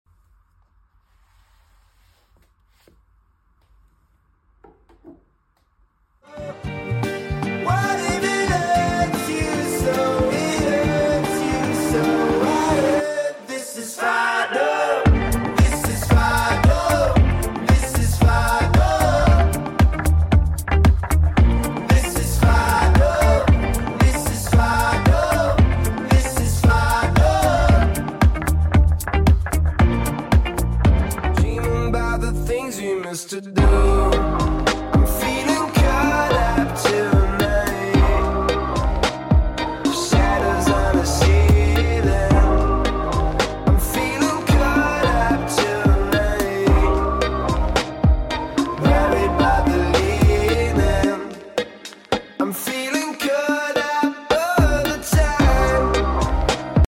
synth-based sound